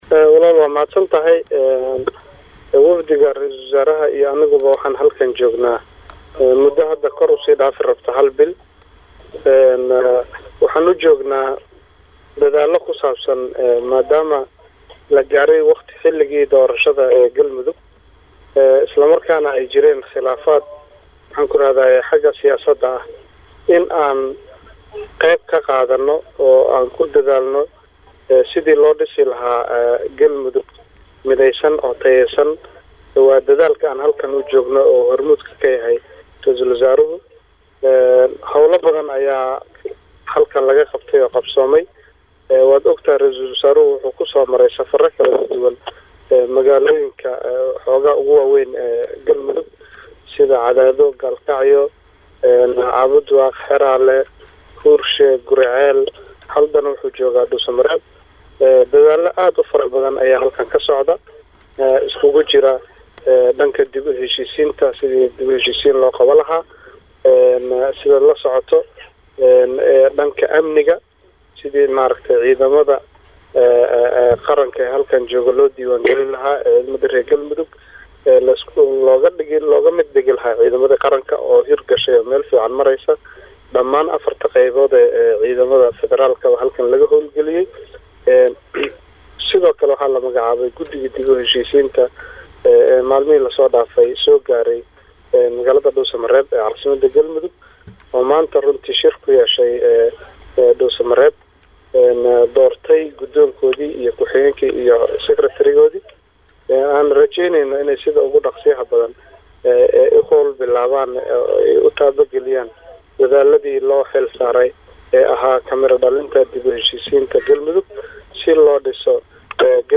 HALKAAN HOOSE KA DHAGEYSO WAREYSIGA